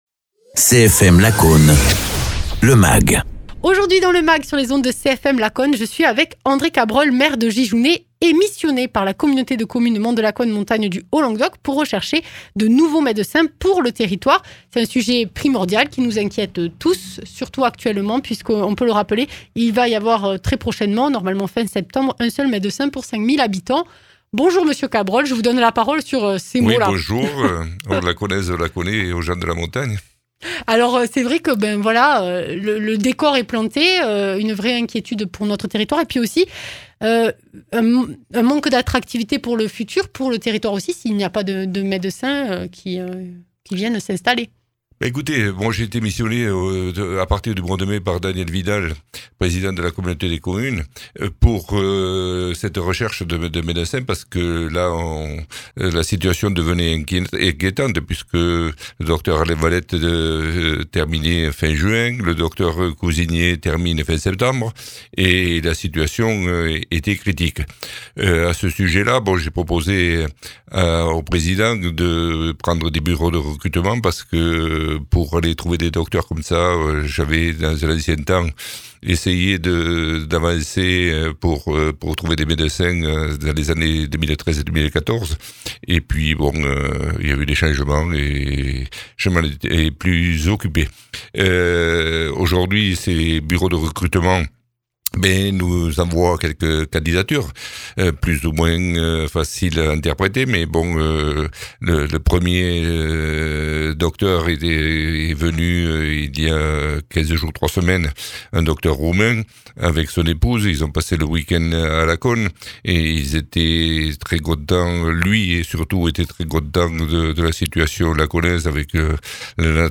Interviews
Invité(s) : André Cabrol, maire de Gijounet et missionné par la communauté de communes Monts de Lacaune, montagne du Haut Languedoc (Tarn).